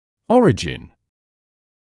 [‘ɔrɪʤɪn][‘ориджин]начало; происхождение